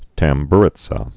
(tăm-brĭt-sə, tămbə-rĭtsə)